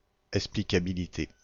Ääntäminen
Ääntäminen France, Bordeaux: IPA: /ɛk.spli.ka.bi.li.te/ Haettu sana löytyi näillä lähdekielillä: ranska Käännös Substantiivit 1. explicabilidade Määritelmät Substantiivit Caractère de ce qui est explicable .